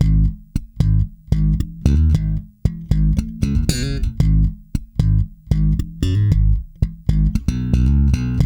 -JP.THUMB.G#.wav